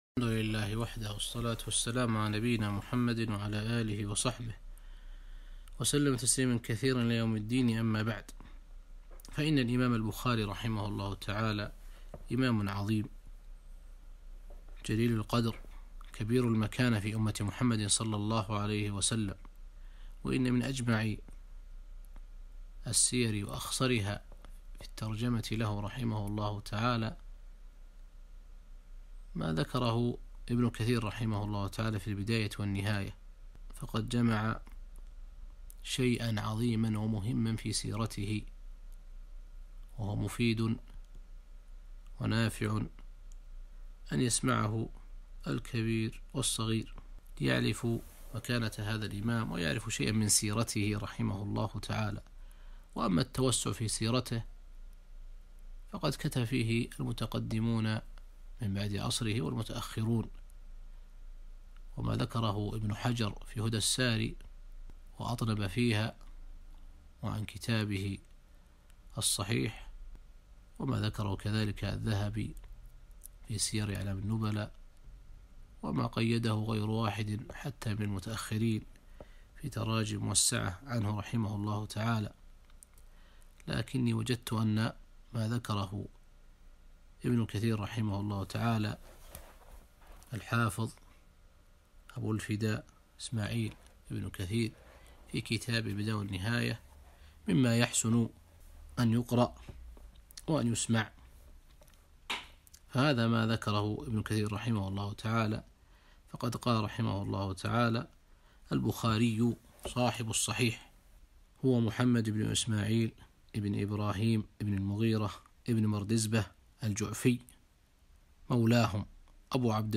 كلمة - من سيرة الإمام البخاري رحمه الله في البداية والنهاية